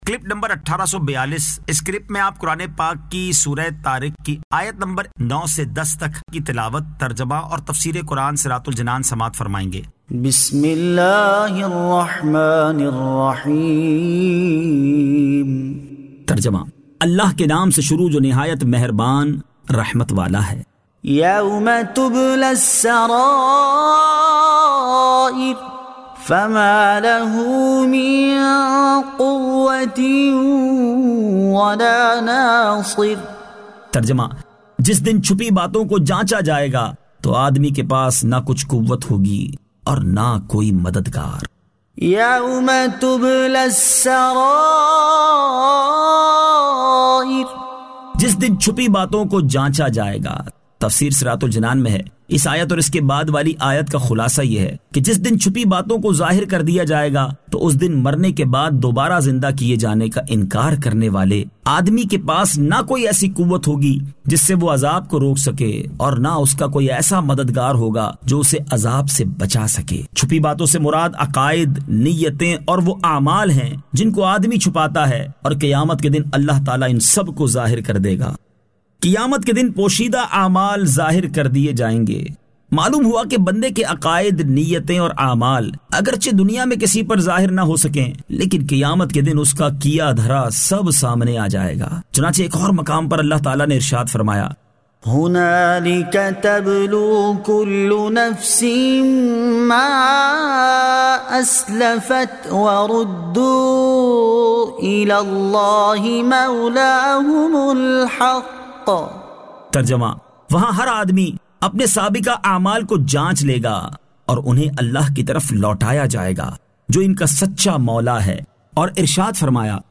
Surah At-Tariq 09 To 10 Tilawat , Tarjama , Tafseer